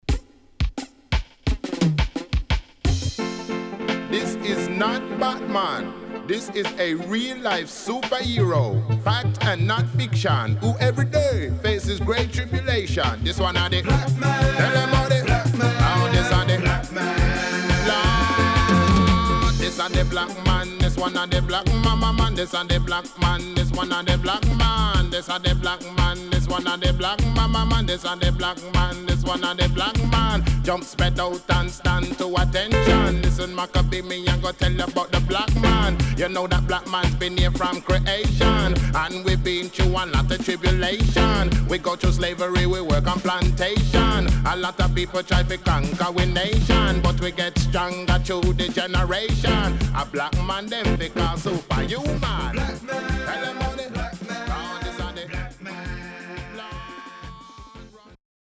HOME > Back Order [DANCEHALL DISCO45]